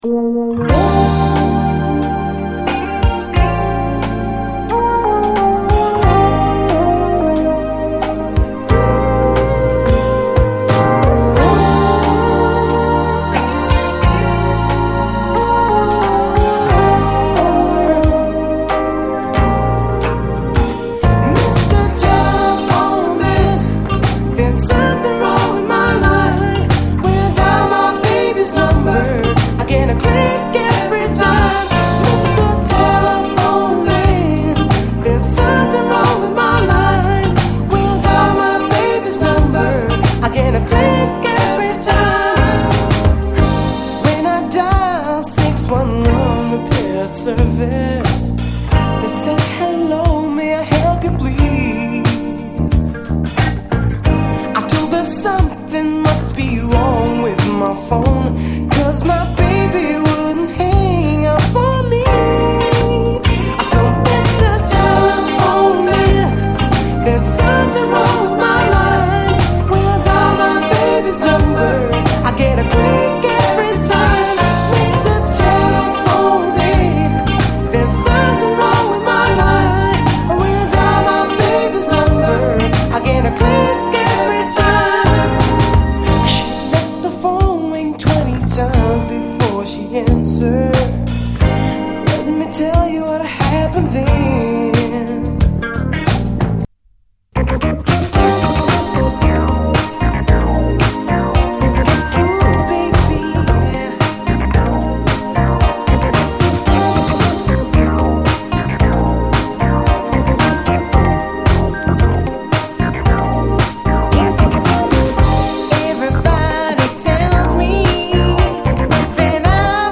子供声とスティール・パンがかわいい
メロウ・トロピカル・ソウル